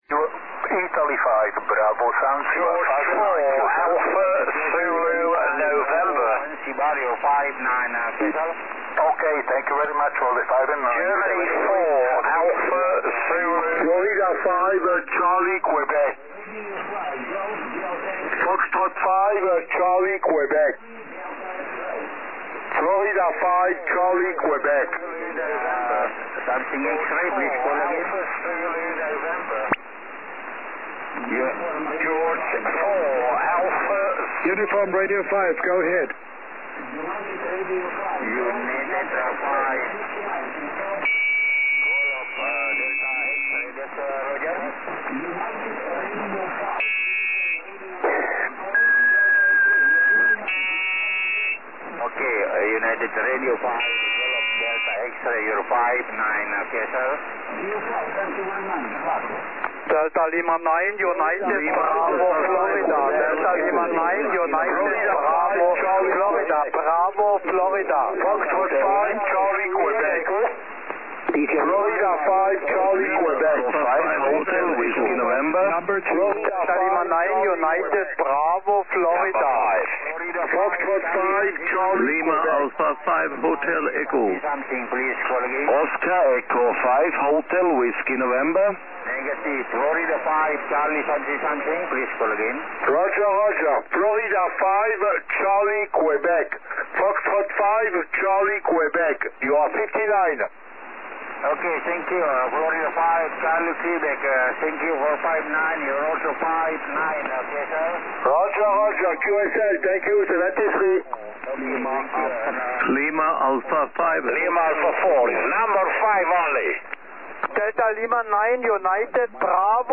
20 SSB